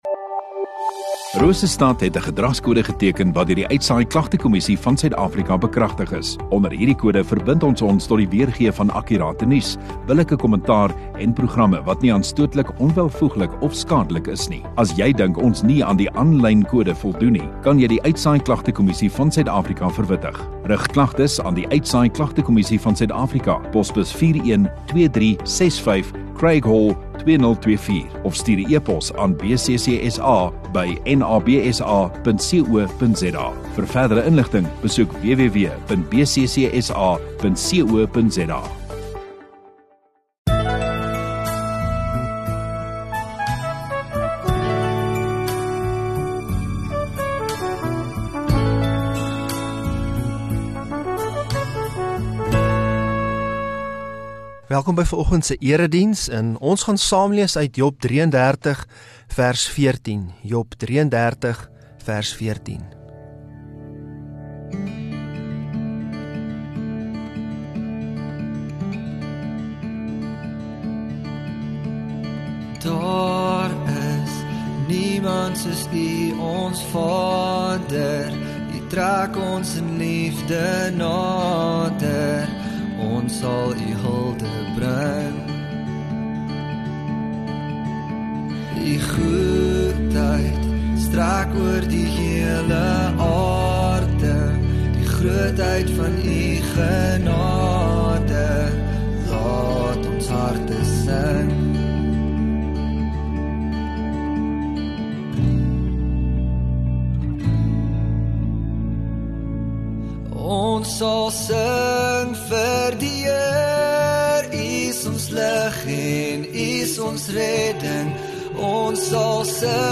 19 Jan Sondagoggend Erediens